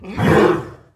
combat / creatures / horse
attack2.wav